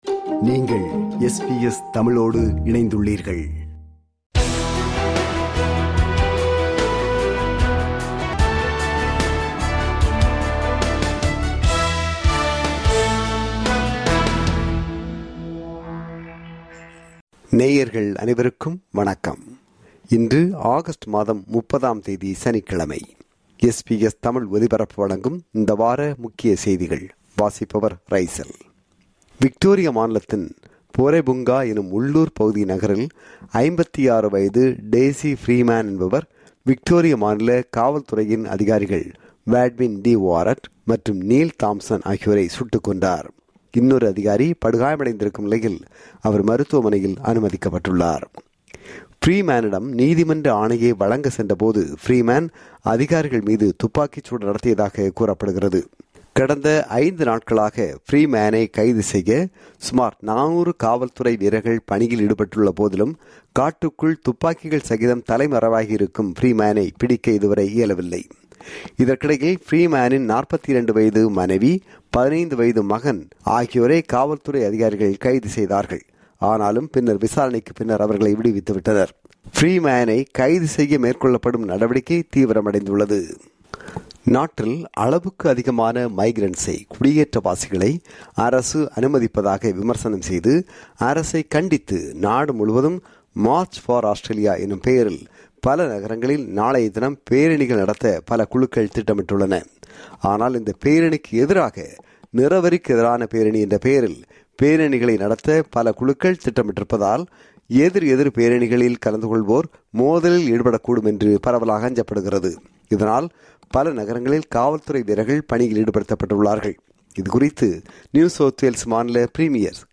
ஆஸ்திரேலிய, உலக செய்திகளின் இந்த வார தொகுப்பு